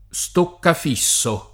Stokkaf&SSo] s. m. — antiq. stoccofisso [Stokkof&SSo], e più antiq. altre forme usate dal ’400 all’800 come stocfis [Stokf&S] e sim., più vicine, senz’adattam. morfologico, all’etimo neerl. stokvis «pesce a bastone»